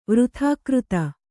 ♪ vyathākřta